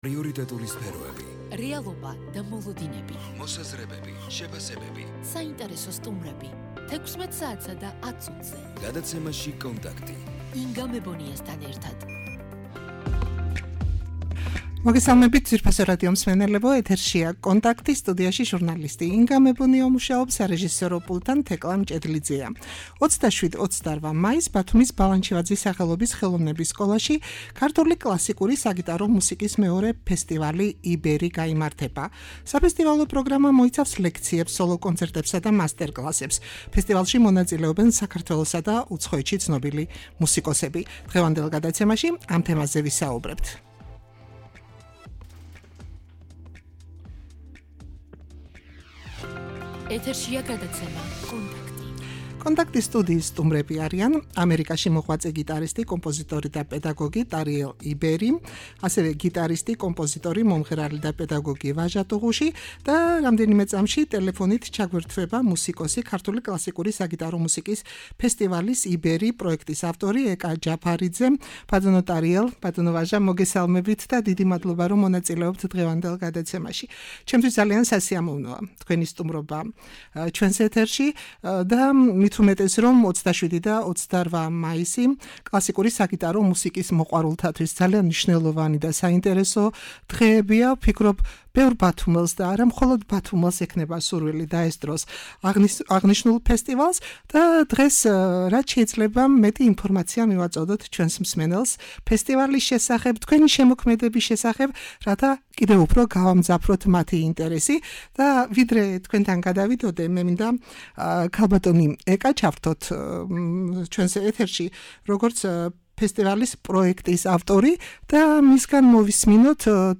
# ქართული კლასიკური საგიტარო მუსიკის ფესტივალი „იბერი“